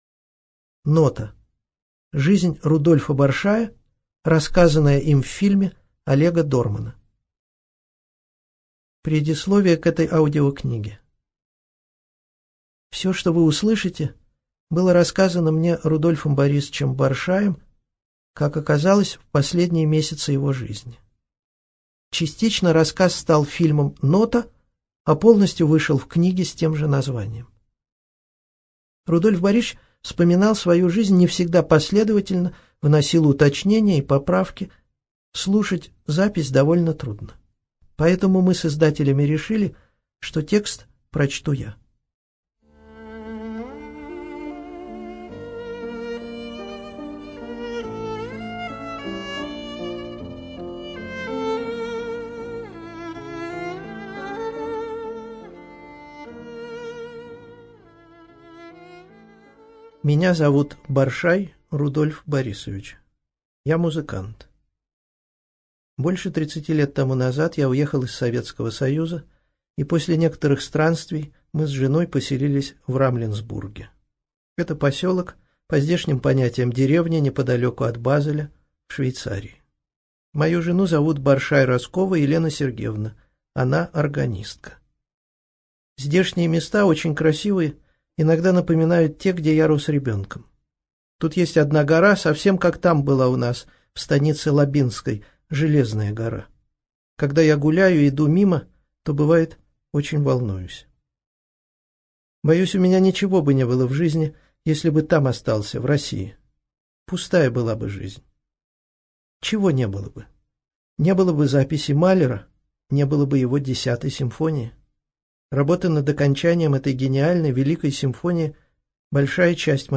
ЧитаетАвтор
ЖанрБиографии и мемуары